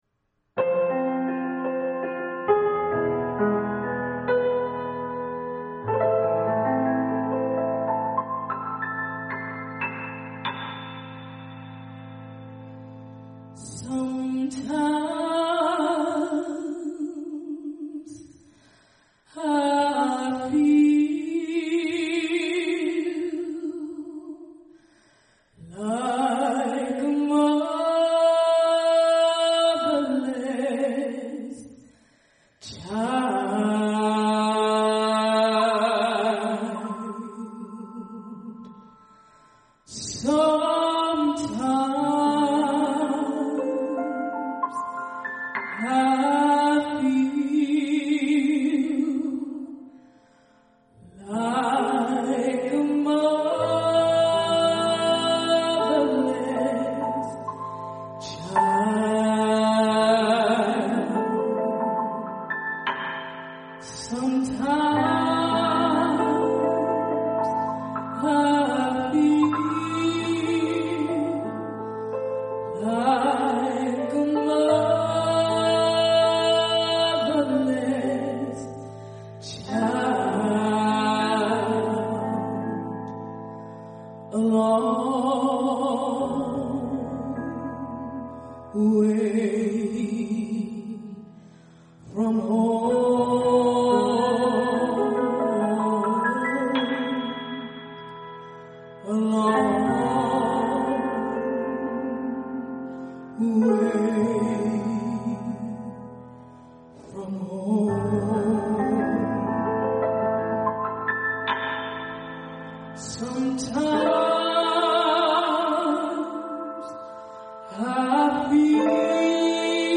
The melancholy tune of the melanin people—in chorus with their rugged hands, warped skin, and fading memories of “home”—expresses a similarly puzzled sentiment.
Included are original recordings of the songs.